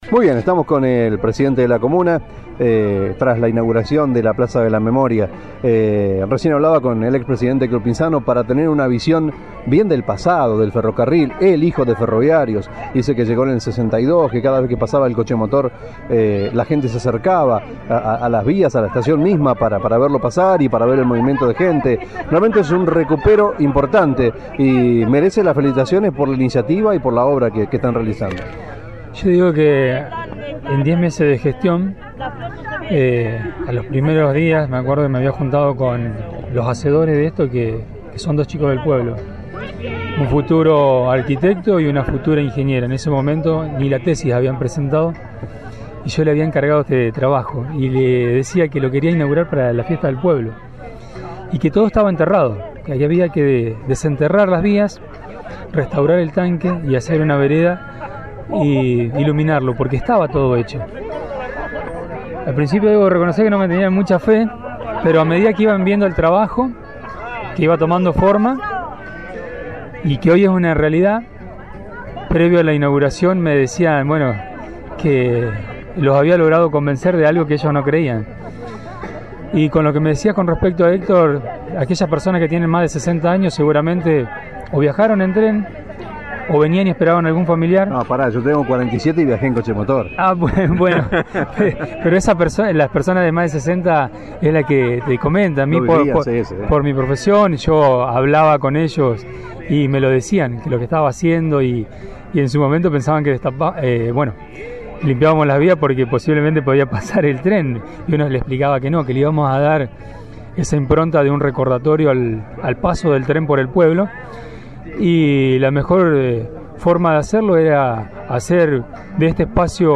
Nota al Pte Comunal Diego Vargas - Inauguracion Pza Memoria (10-10-14)